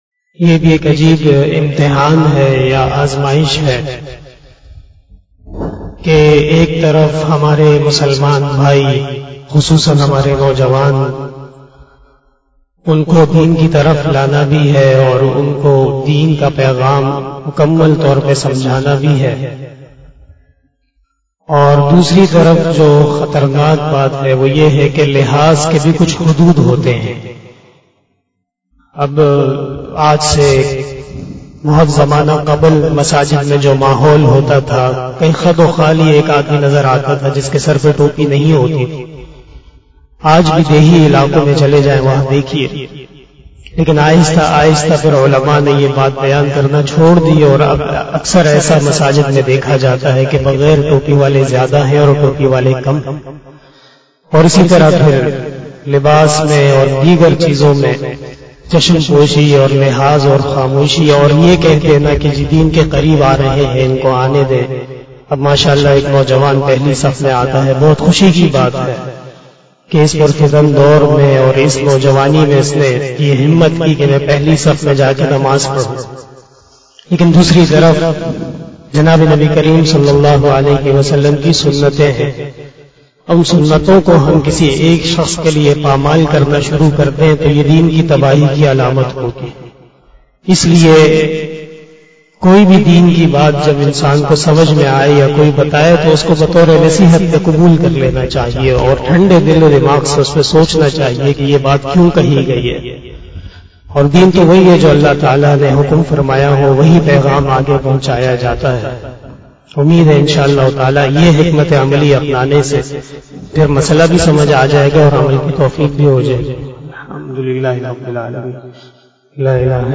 060 After Asar Namaz Bayan 25 September 2021 (17 Safar 1443HJ) Saturday]
بیان بعد نماز عصر